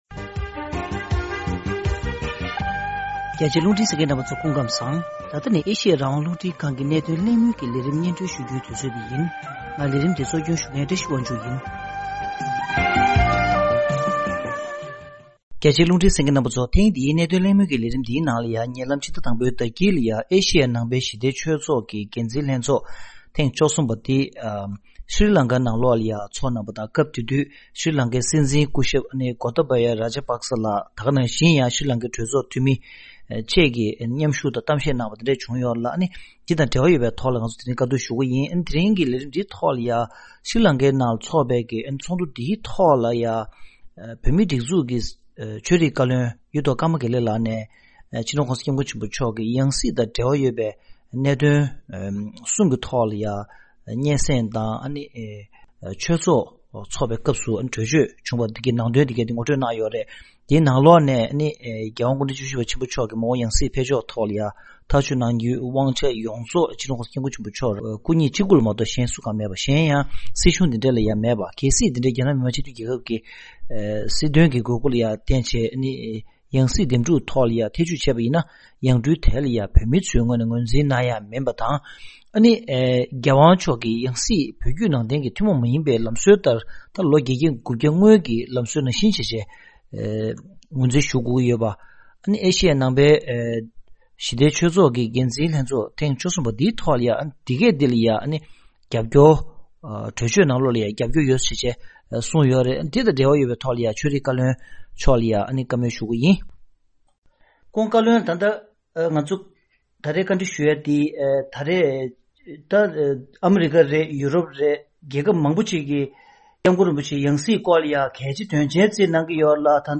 འདི་དང་འབྲེལ་བའི་ཐད་ཐེངས་འདིའི་གནད་དོན་གླེང་མོལ་གྱི་ལས་རིམ་ནང་།